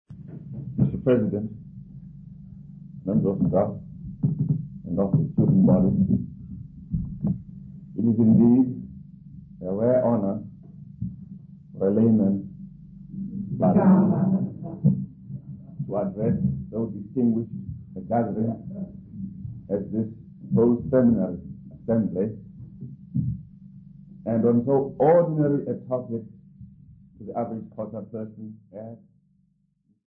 DDC234a-04.mp3 of Address by Chief Burns-Ncamashe 1